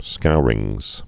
(skourĭngz)